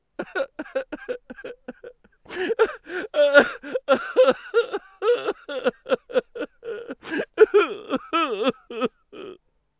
男人悲伤哭声音效免费音频素材下载